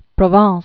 (prō-väɴs) 1223?-1291.